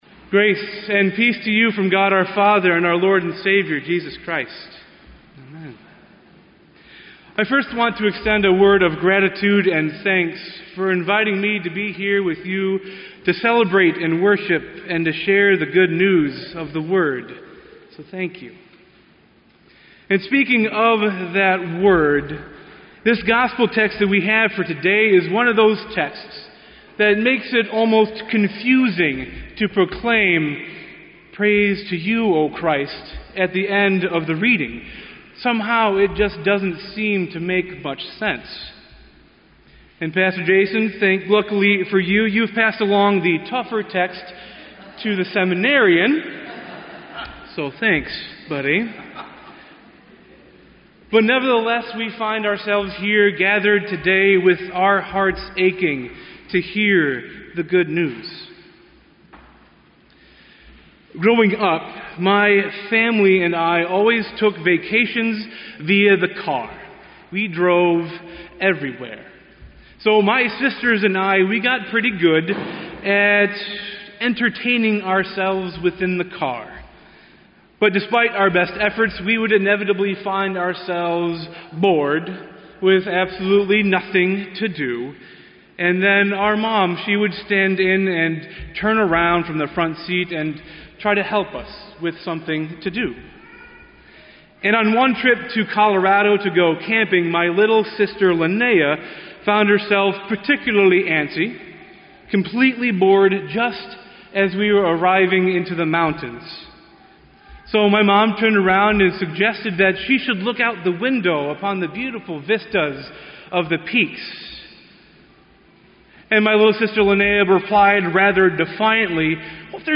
Wicker Park Lutheran Church Preaching Fall 2015